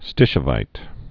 (stĭshə-vīt)